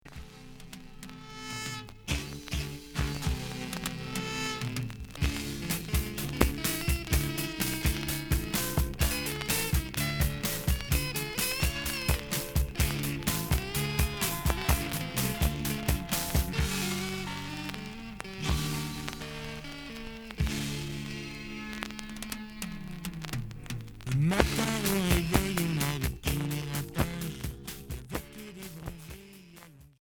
Rock Unique 45t retour à l'accueil